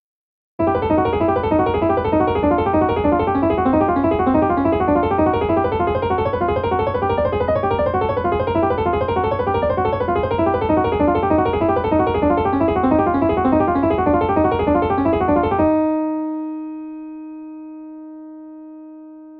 This first excerpt is a 4-note arpeggio taking a random walk on a chromatic scale, with velocity and note length similarly following a Brownian pattern (that is, incrementing or decrementing by a small amount each note event).
As a comparison, here’s the same process but with fixed dynamics. Its machinic character is pretty glaring.
Excerpt #1 (flat dynamics)